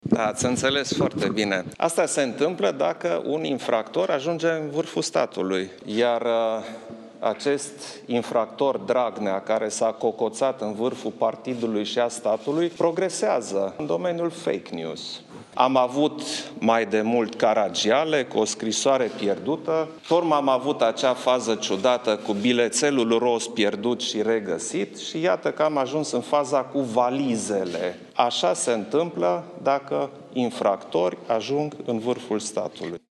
Aşa se întâmplă dacă infractori ajung în vârful statului”, a declarat Iohannis la Palatul Cotroceni.